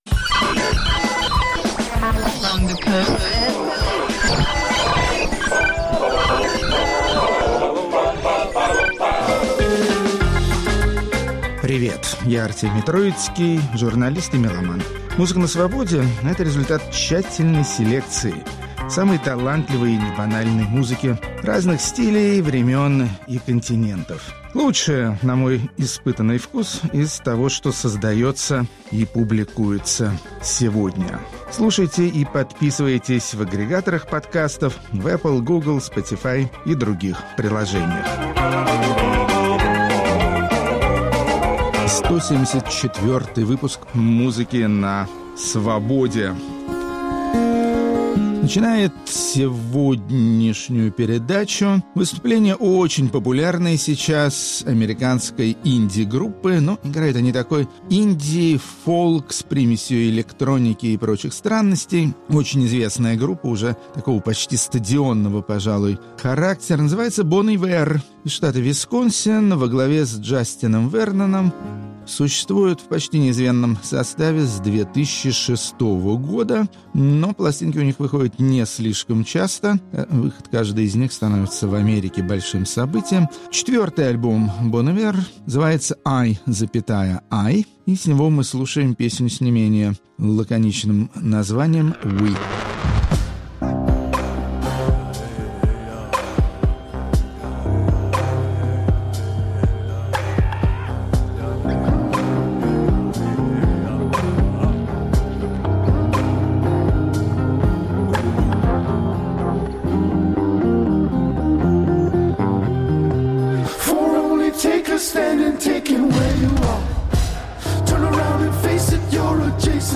Глубинные исполнители из Финляндии, живущие вне больших и маленьких городов. Рок-критик Артемий Троицкий считает заполярный финский блюз самостоятельным культурным явлением, достойным всяческого прослушивания и восхищения.